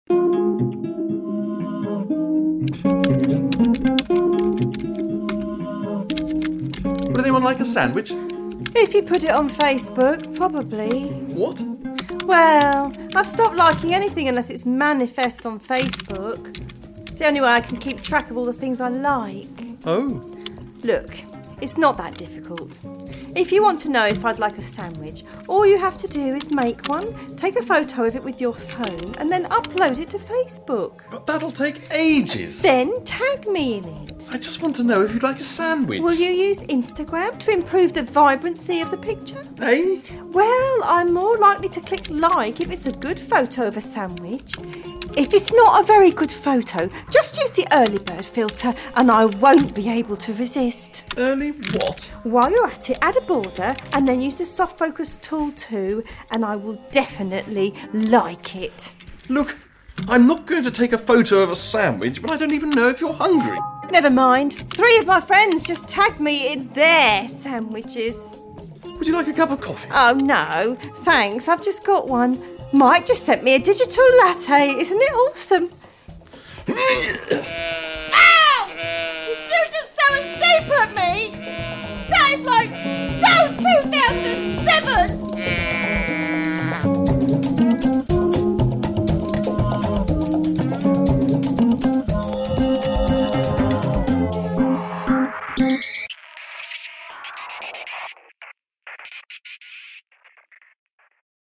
A sketch from Episode 6 of comedy podcast 4amcab